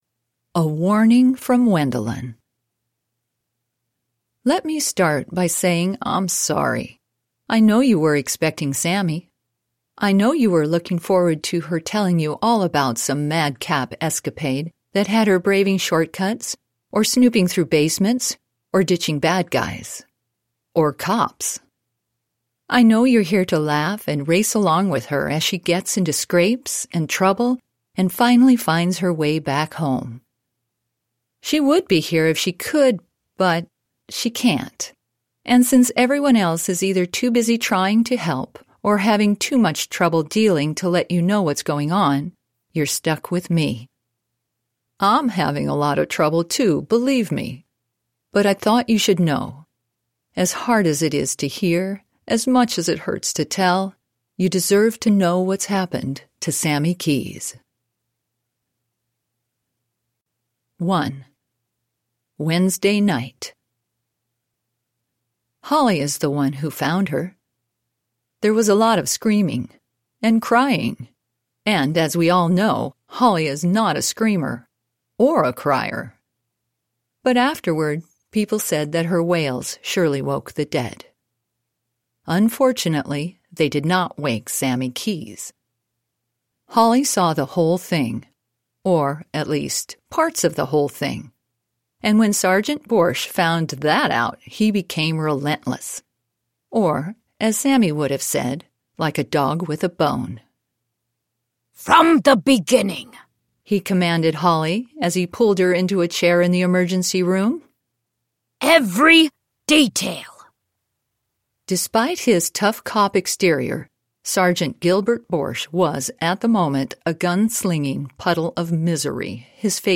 Middle Grade Audiobooks